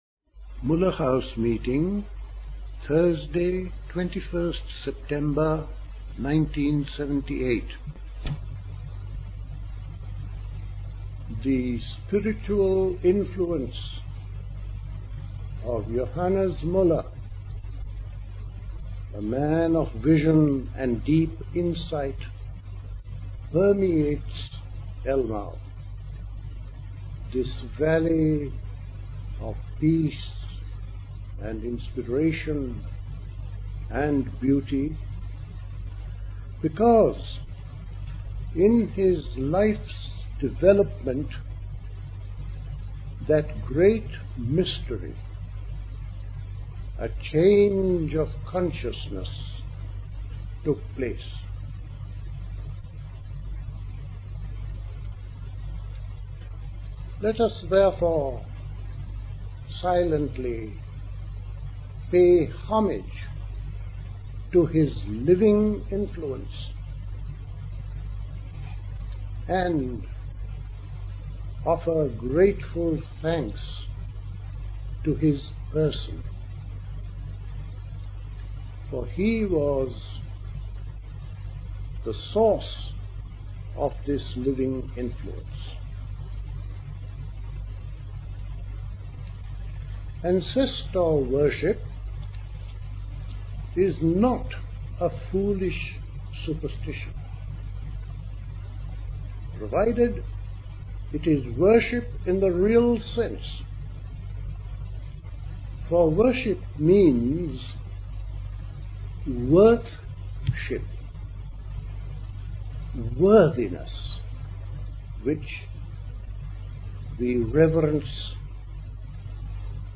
A talk
at Elmau, Bavaria